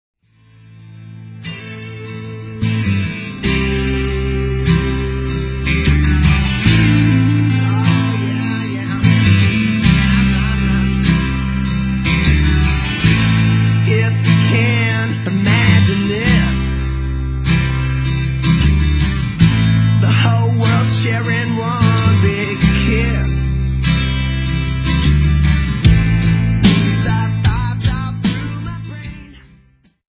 GUITAR
DRUMS
VOCALS
BASS